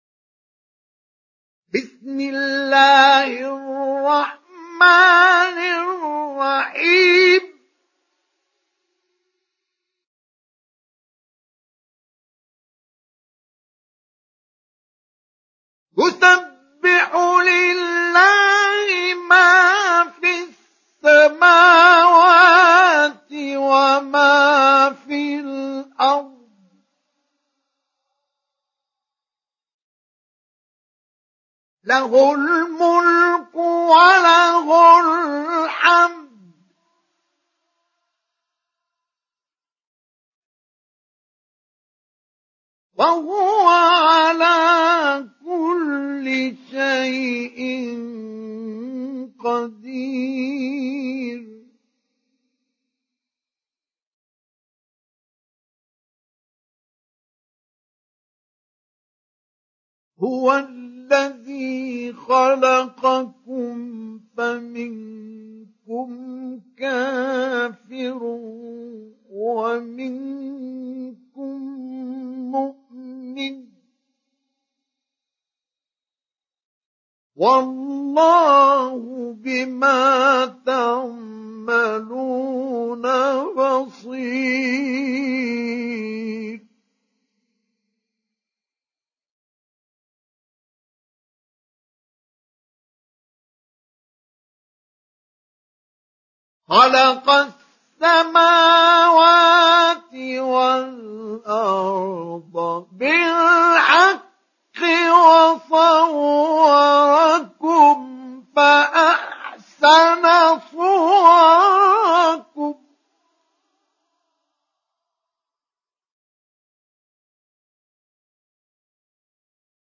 Tegabun Suresi mp3 İndir Mustafa Ismail Mujawwad (Riwayat Hafs)